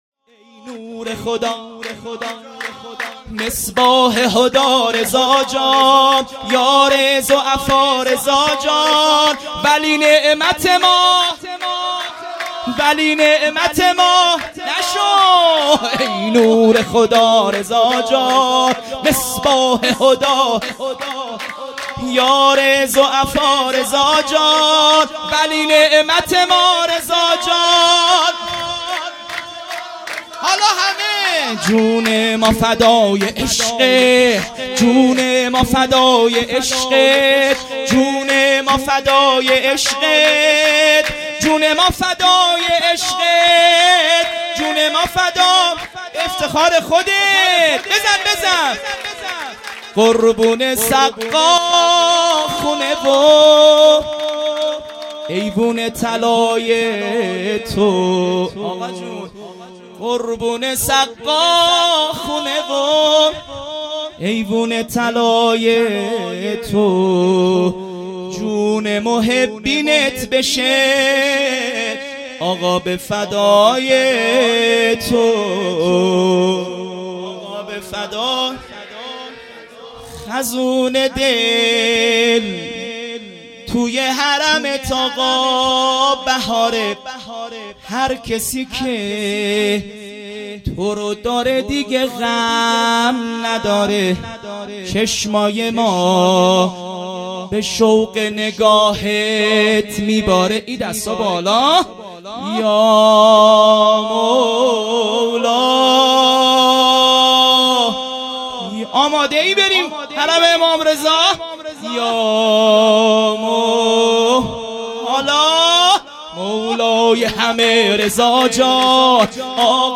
هیئت مکتب الزهرا(س)دارالعباده یزد - سرود ۲ | نور خدا رضا جان مداح